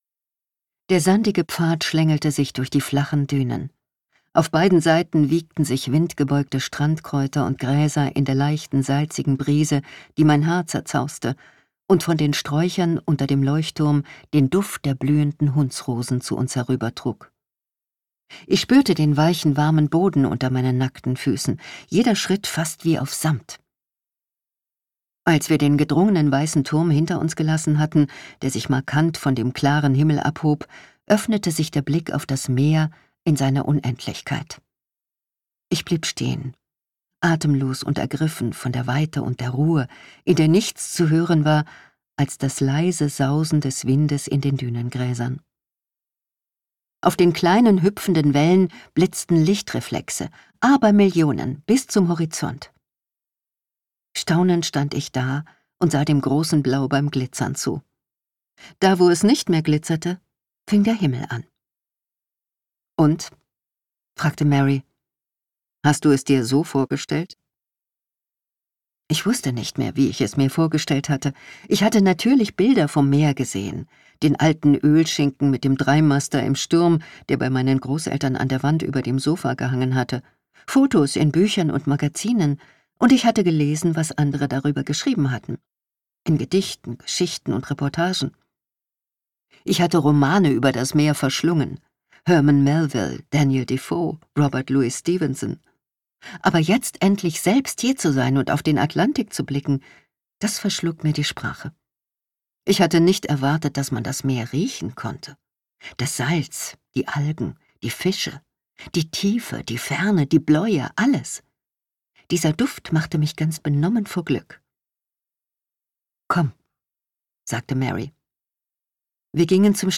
Details zum Hörbuch